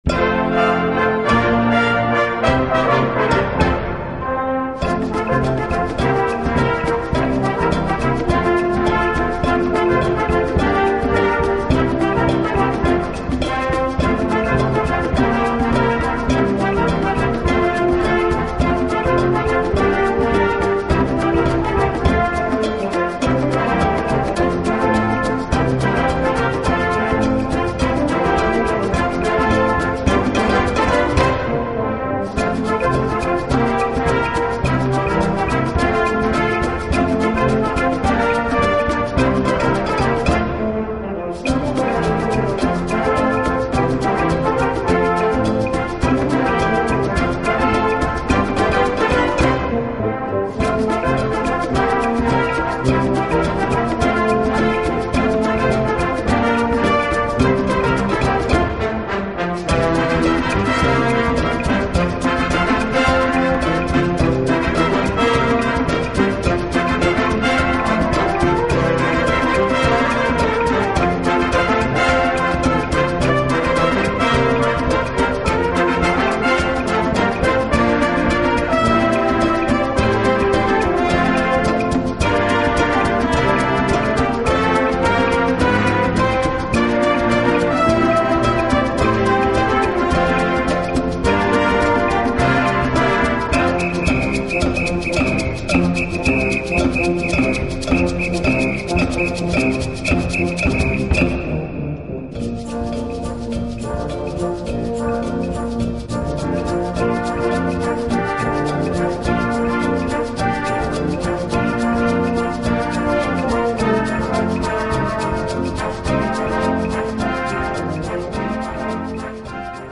Gattung: Moderne Unterhaltungsmusik
4:08 Minuten Besetzung: Blasorchester Zu hören auf